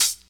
Closed Hats
Boom-Bap Hat CL 96.wav